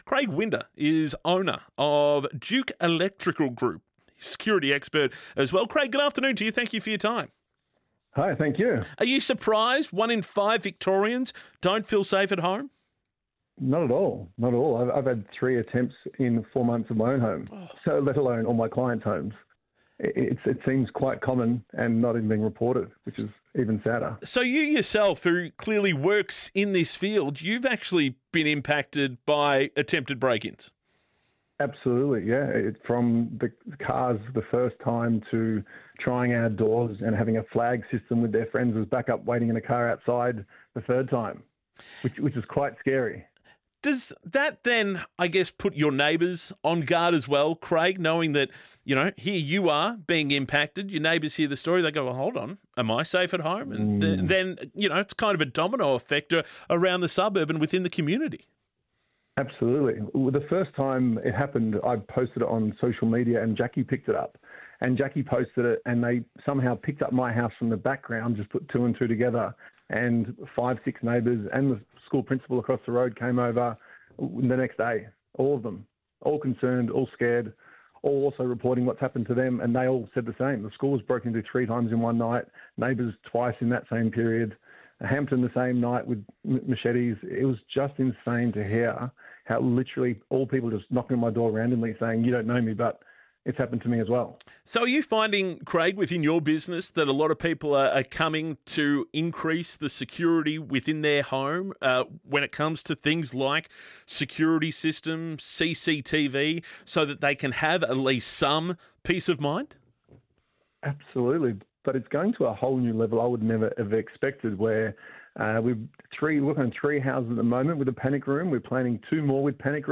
DUKE Electrical Group Interview On 3AW